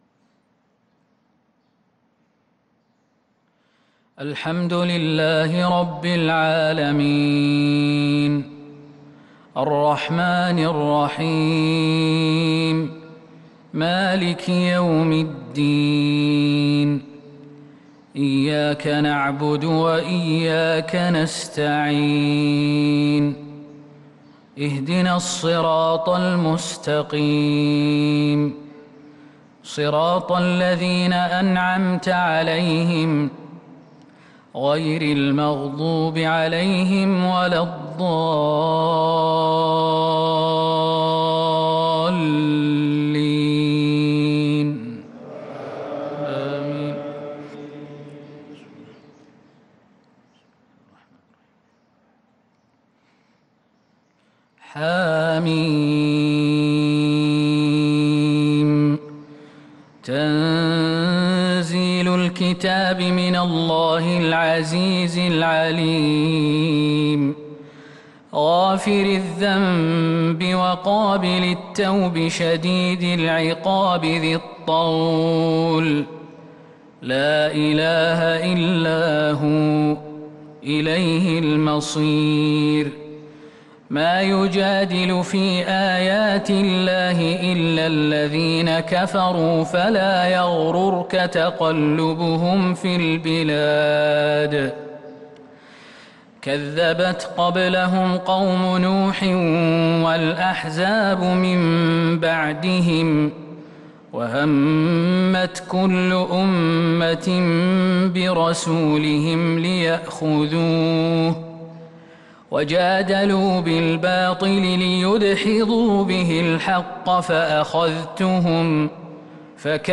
صلاة الفجر للقارئ خالد المهنا 15 رمضان 1443 هـ